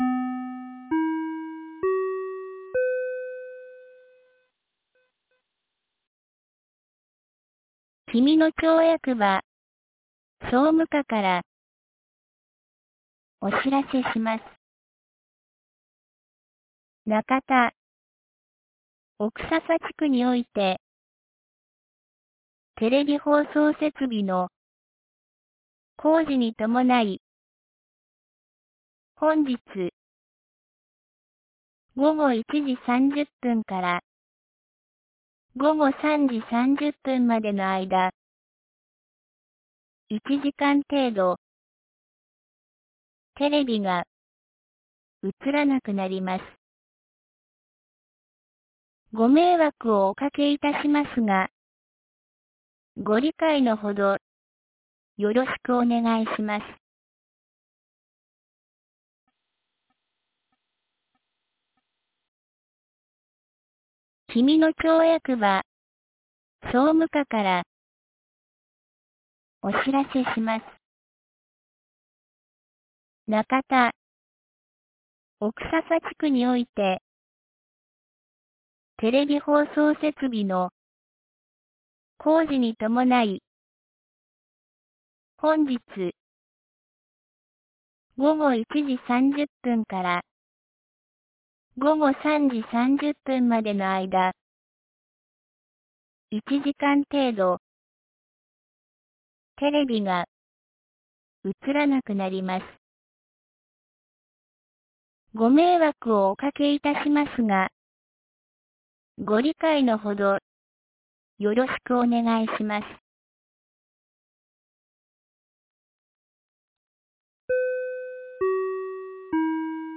2023年12月12日 12時37分に、紀美野町より小川地区へ放送がありました。